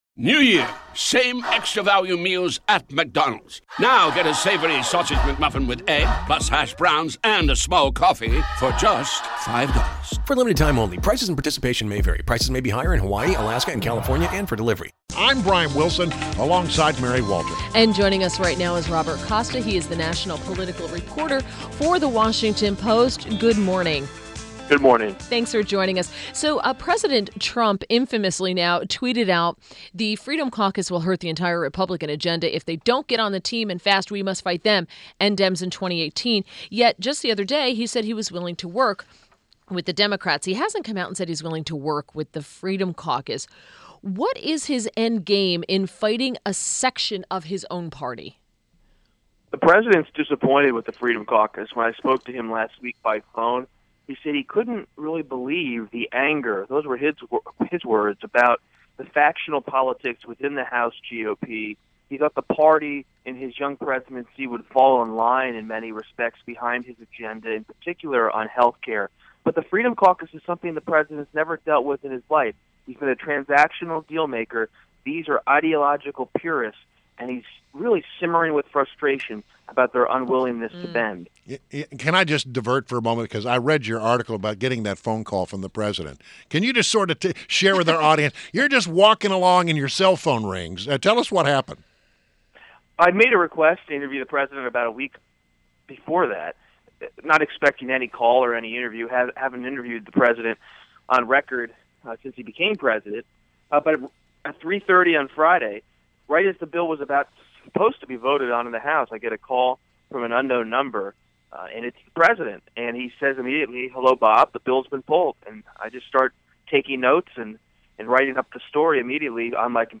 INTERVIEW – ROBERT COSTA – National political reporter, Washington Post